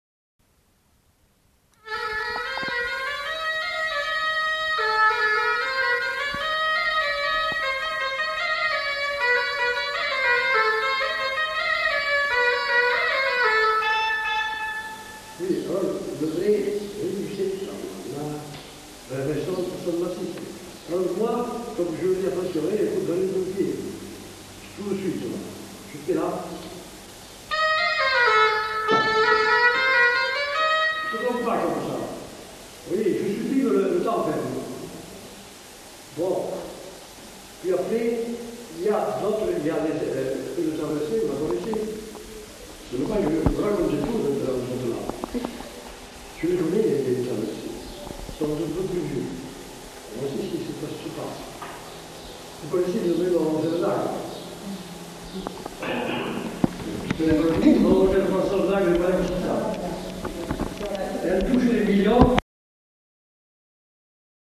Aire culturelle : Couserans
Département : Ariège
Genre : morceau instrumental
Instrument de musique : hautbois
Danse : bourrée
Notes consultables : Commentaires du musicien et courte reprise du morceau.